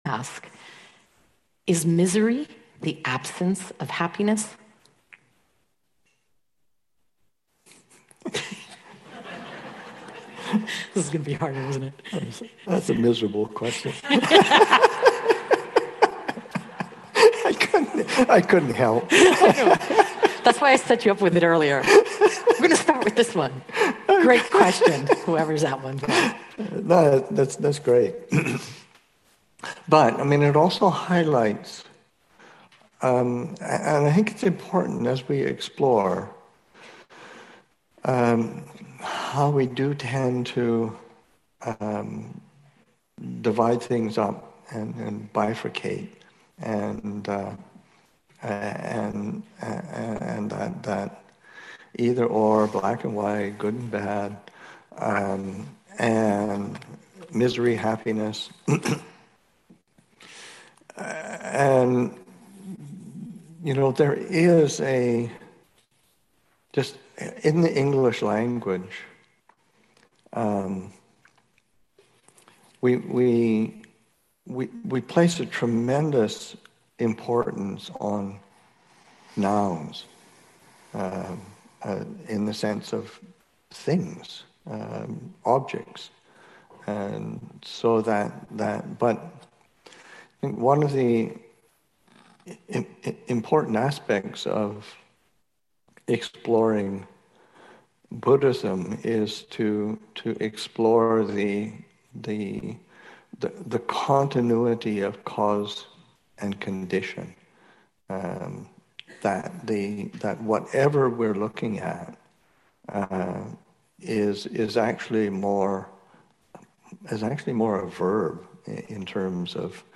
Spirit Rock Daylong, Aug. 20, 2023